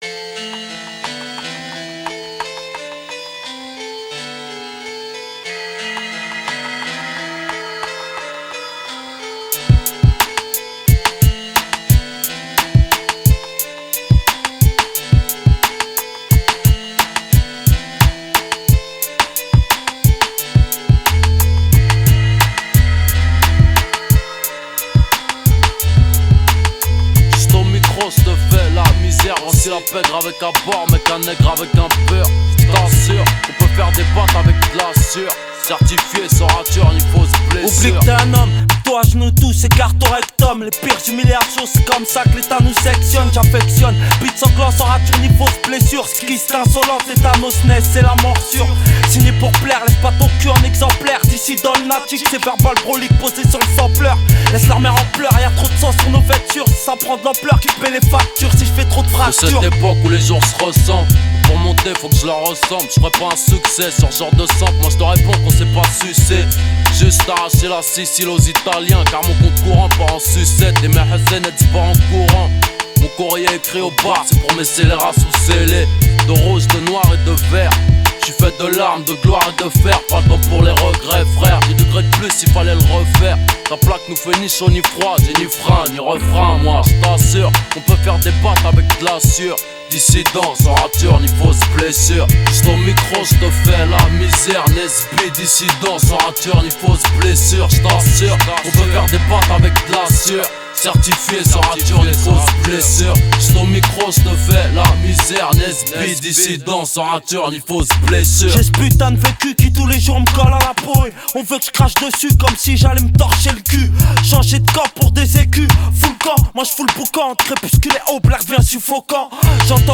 Genres : french rap, french r&b, pop urbaine